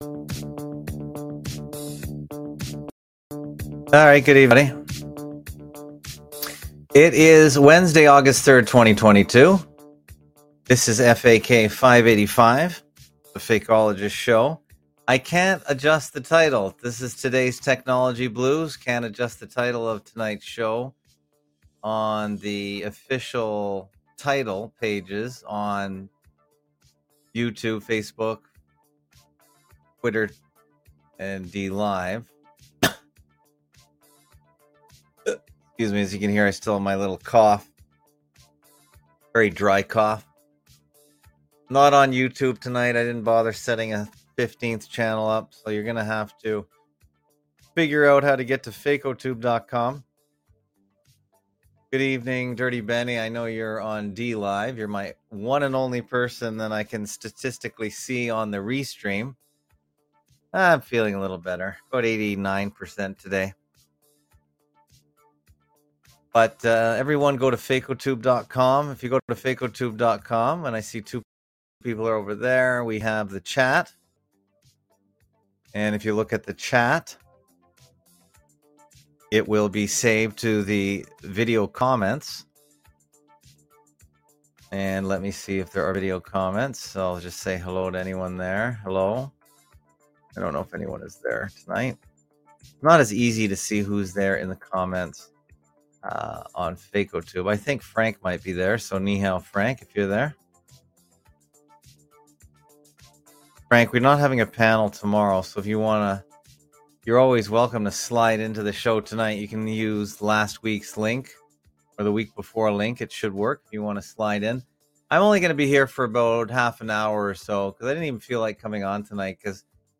Fakeologist show Live Sun-Thu 830pm-900pm EDT